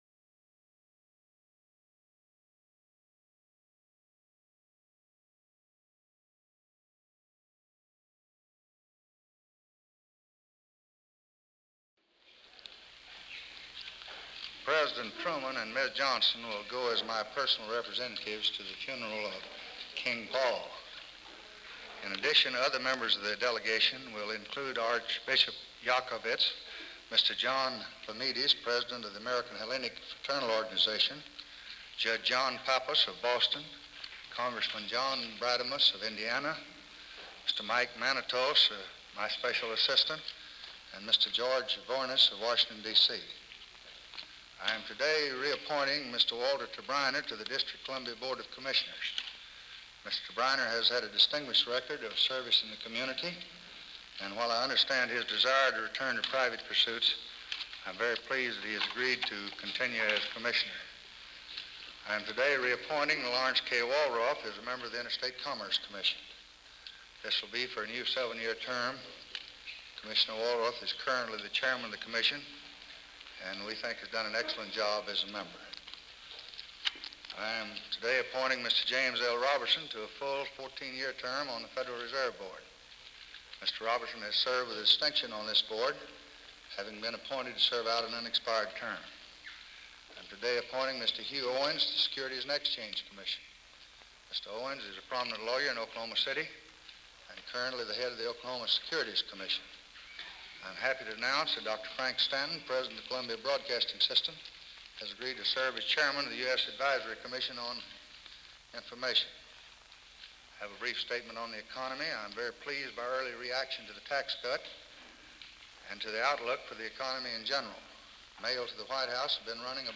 March 7, 1964: Press Conference at the White House | Miller Center
Presidential Speeches | Lyndon B. Johnson Presidency